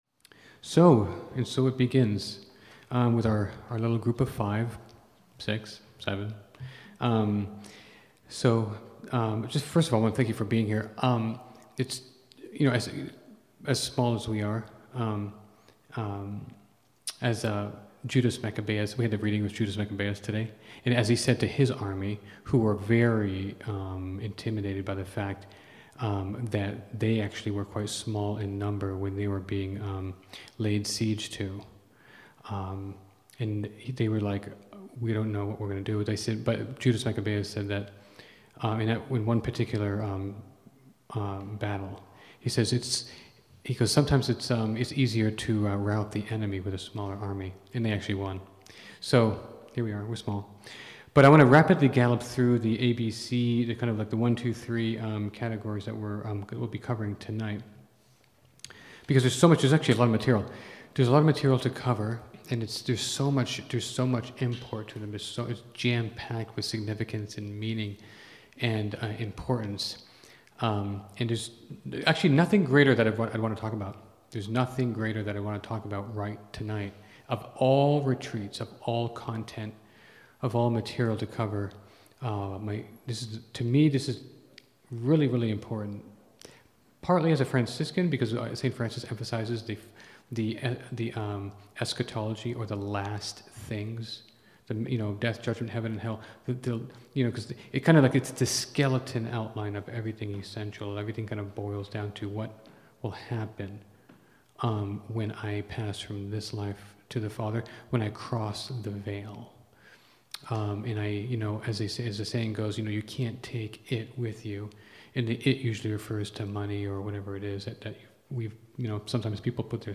preaches a retreat on purgatory called November, Month of Holy Souls. In this first talk, he covers the biblical and traditional roots of purgatory and how the souls in purgatory need prayers and especially masses said for them.